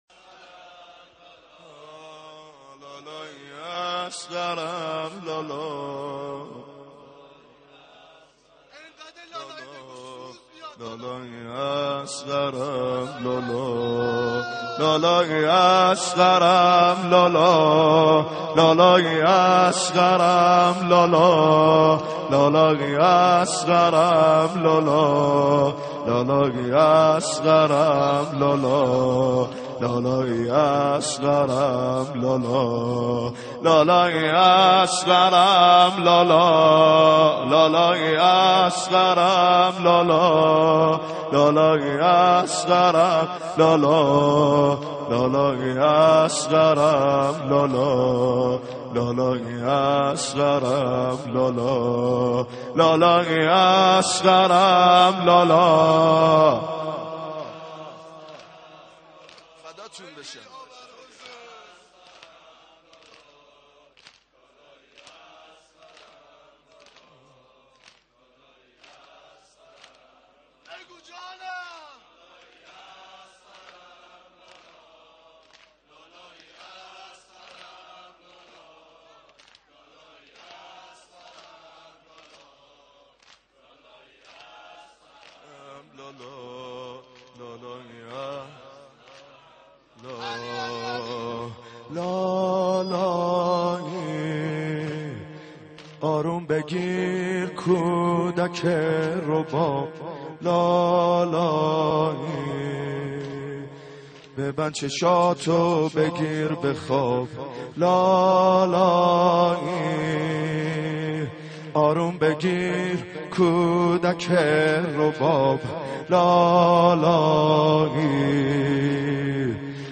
پیش زمینه | لالایی اصغرم لا
مصلی امام خمینی(ره)